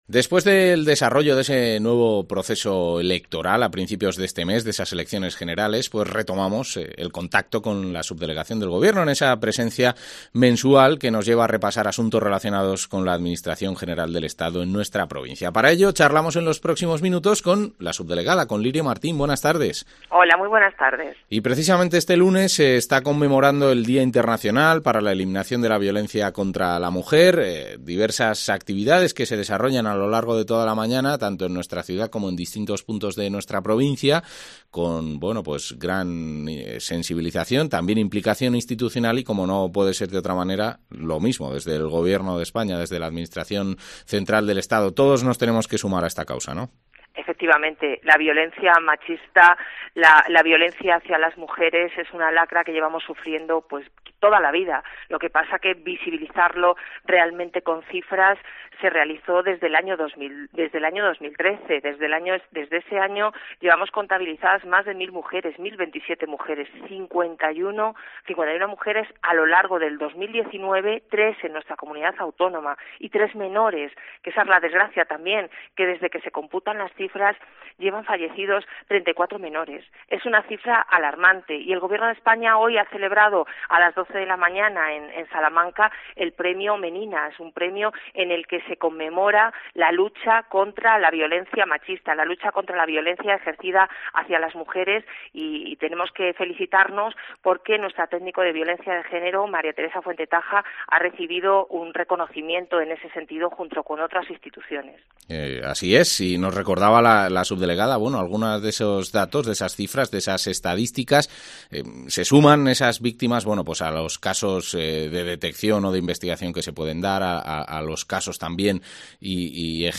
Entrevista subdelegada del Gobierno